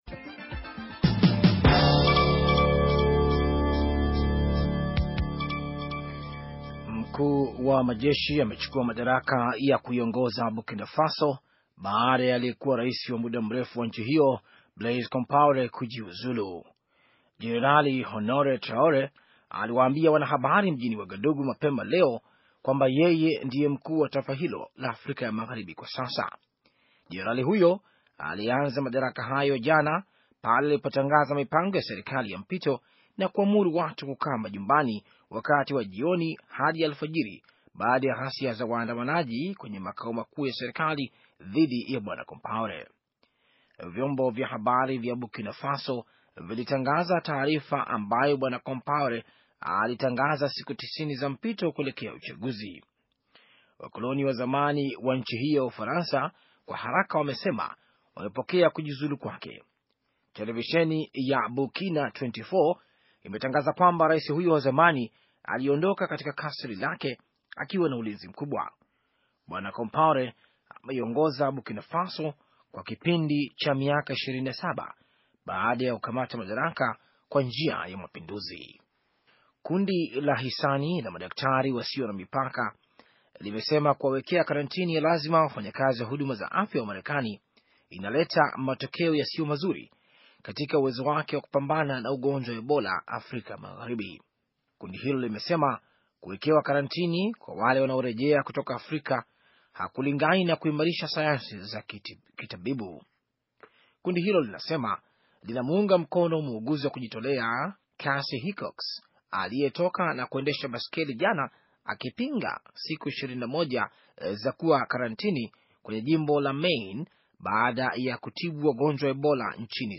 Taarifa ya habari - 6:42